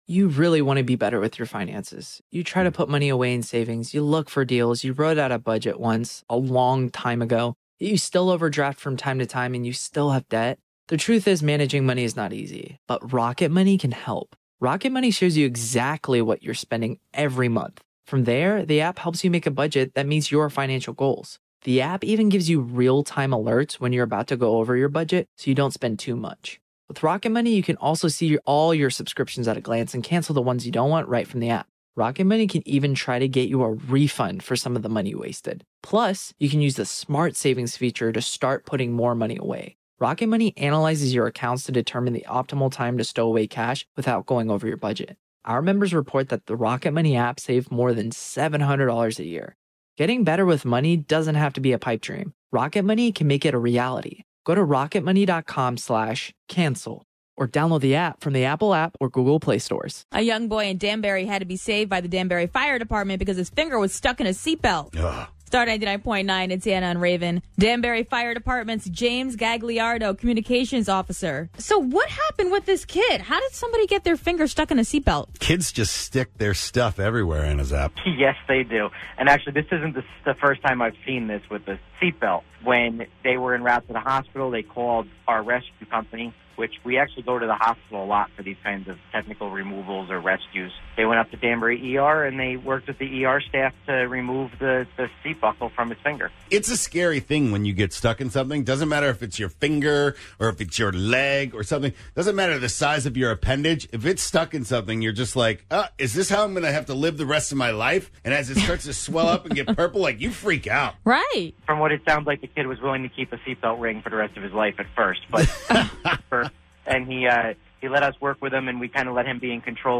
After Danbury Firefighters saved a child's finger after he got it stuck in a seatbelt, everyone called in with stories about how they got stuck and how they got freed!